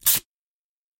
Звуки скотча
Звук резкого отрыва клейкой ленты